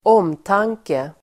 Uttal: [²'åm:tang:ke]